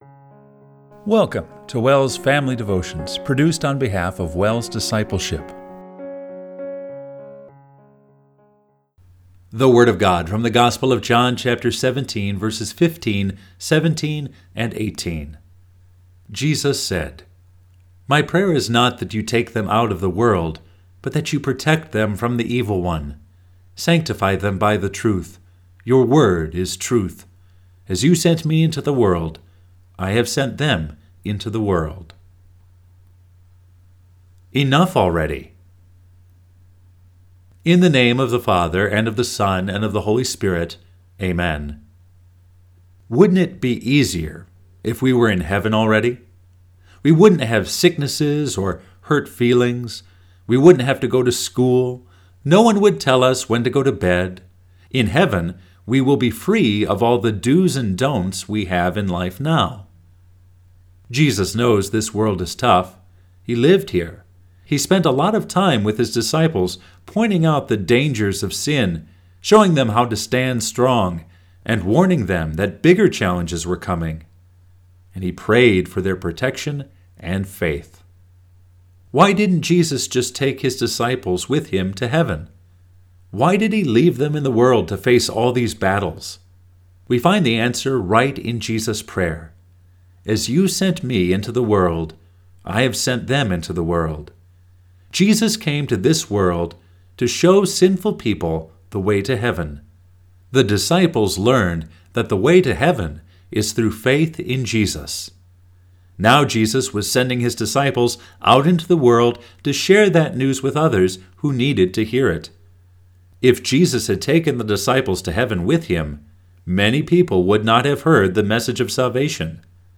Family Devotion – May 17, 2024